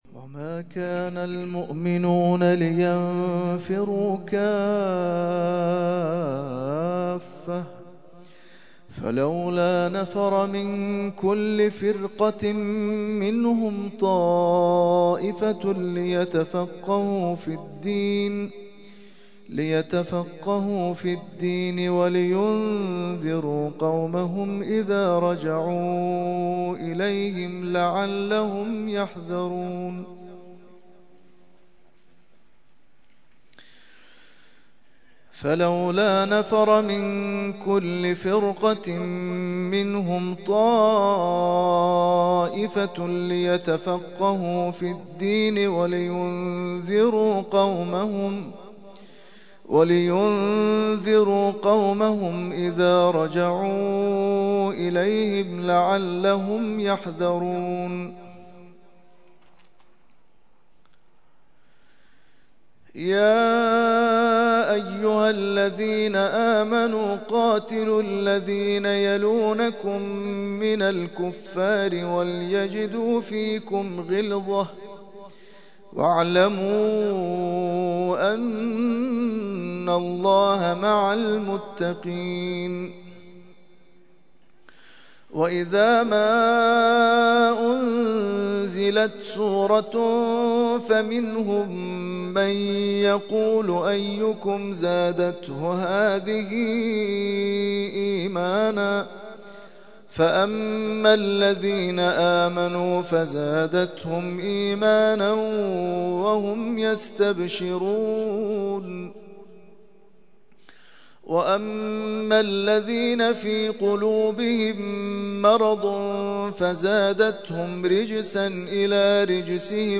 ترتیل خوانی جزء ۱۱ قرآن کریم در سال ۱۳۹۱